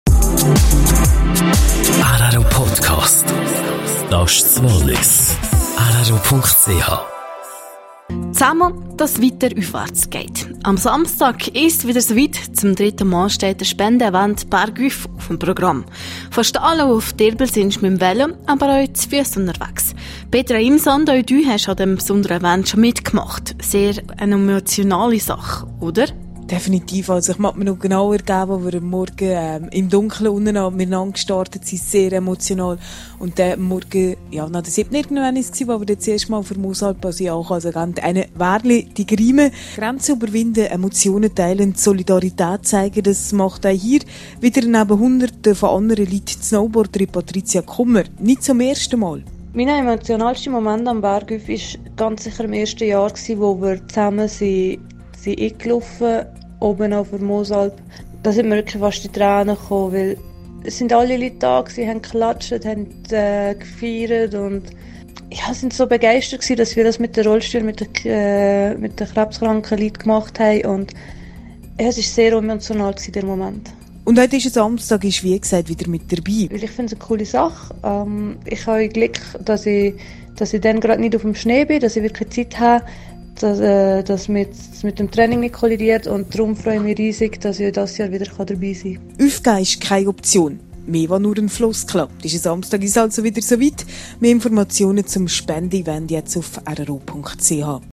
Auch rro wird am Samstag vor Ort sein und vom Spenden-Event berichten./ip Interview mit Patrizia Kummer über den Event Bärgüf (Quelle: rro) Mehr Informationen zum Event